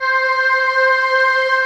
Index of /90_sSampleCDs/Optical Media International - Sonic Images Library/SI1_SlowOrchPad/SI1_SlowMelowPad